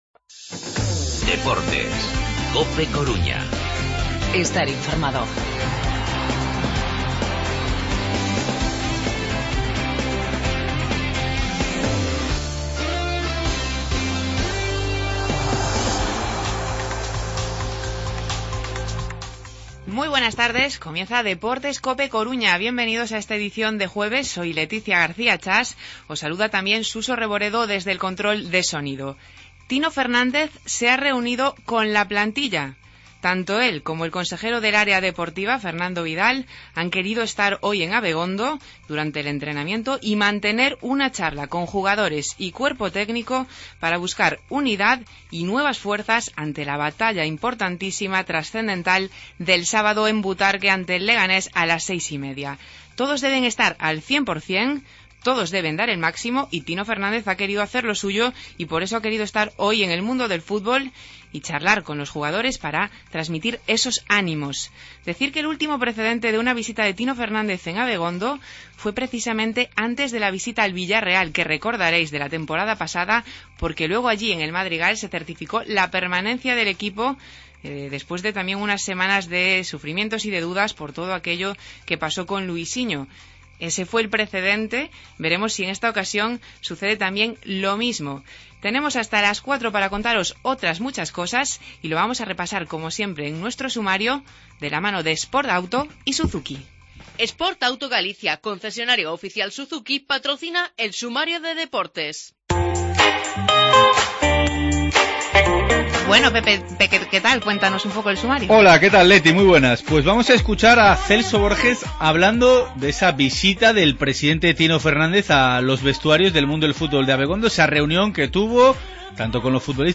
Tino Fernández estuvo en Abegondo reunido con los jugadores y el cuerpo técnico. Escuchamos a Borges hablando de esa visita.